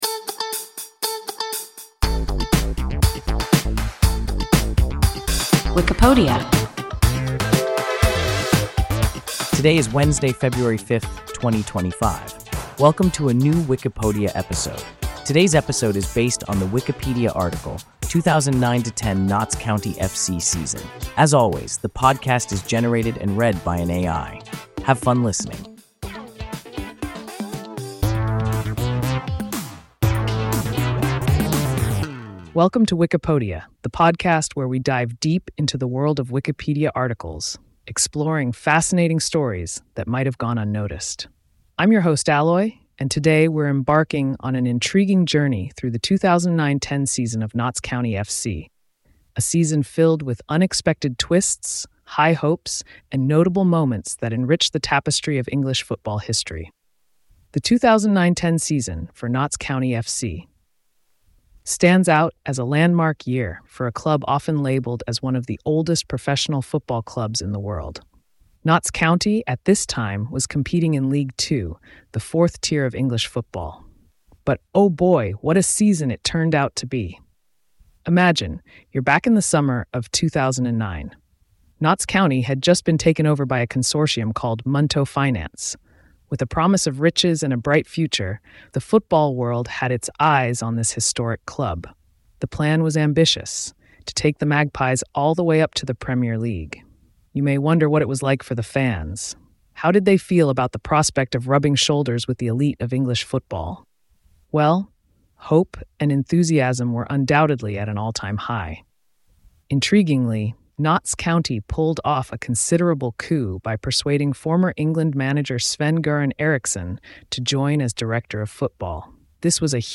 2009–10 Notts County F.C. season – WIKIPODIA – ein KI Podcast